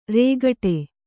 ♪ rēgaṭe